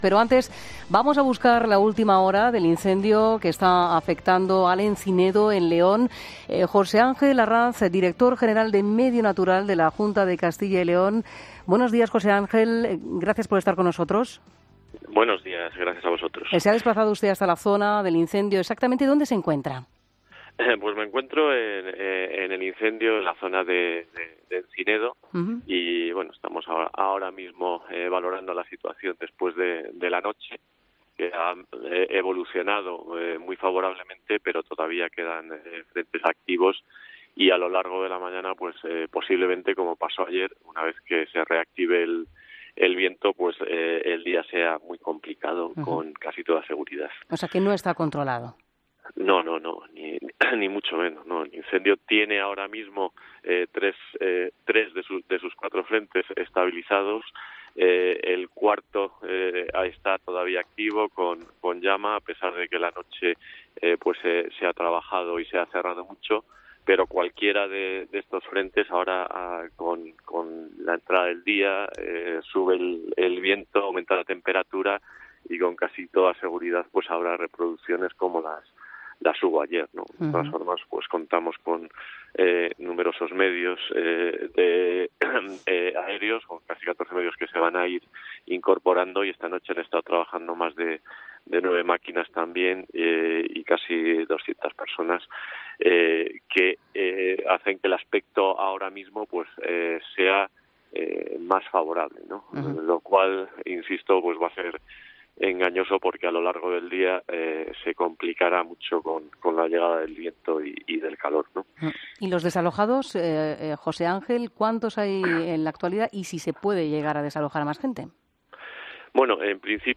José Angel Arranz, director general de Medio Natural de la Junta de Castilla y León, en 'Herrera en COPE'